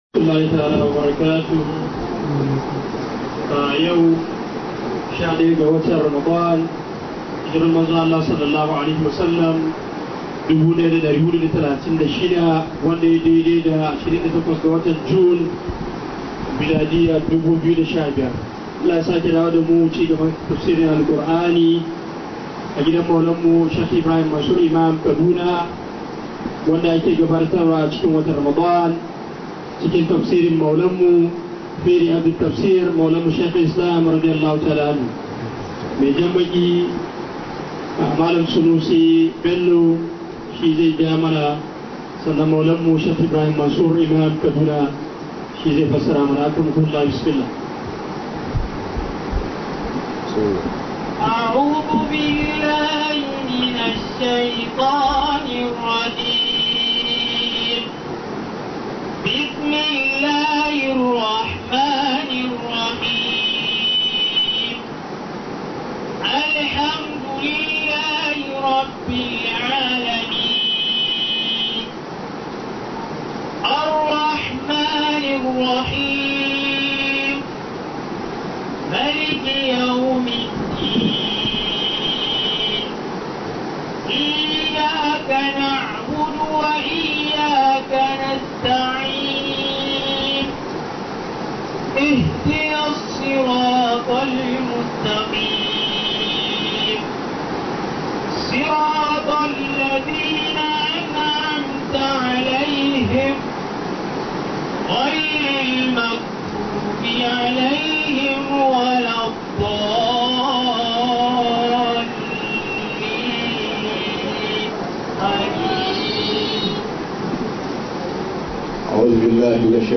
003 HAYATUDEEN ISLAMIC STUDIO TAFSIR 2015.mp3